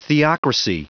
Prononciation du mot theocracy en anglais (fichier audio)
Prononciation du mot : theocracy